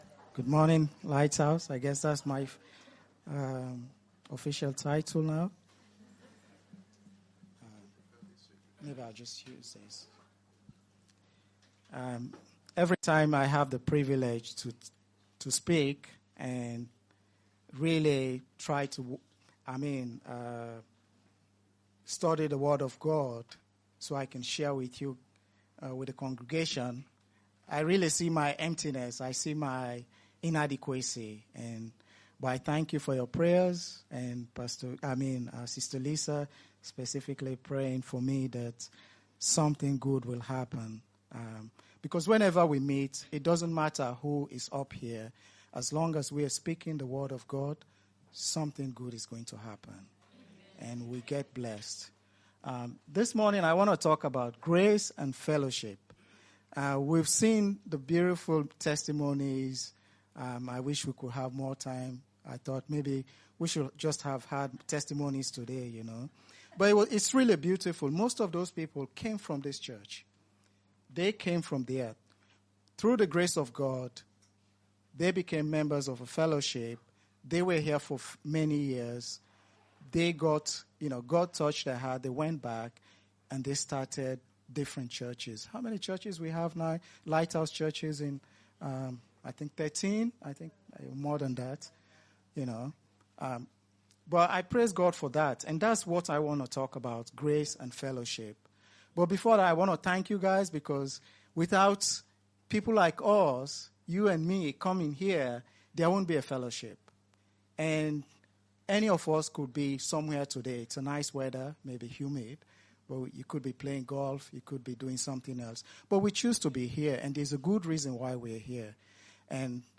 Notes Discussion Grace & Fellowship Sermon by